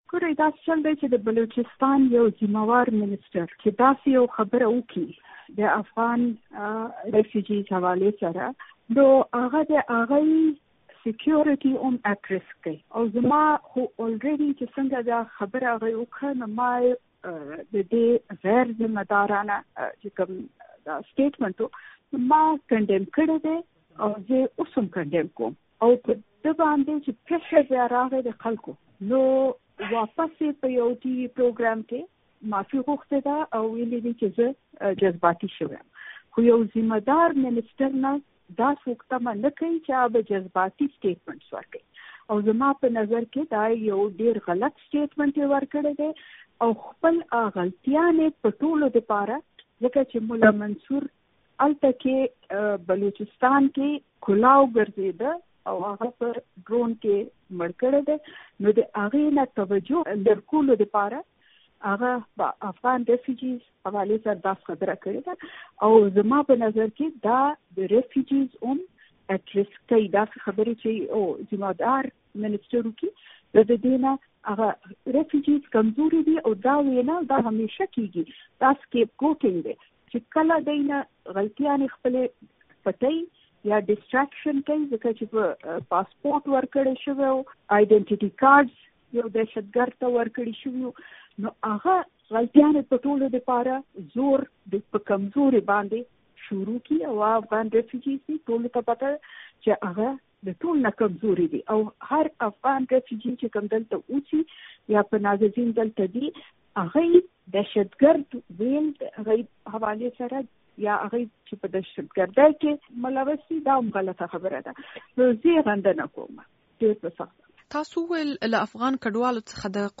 مرکه
له بشرا ګوهر سره مرکه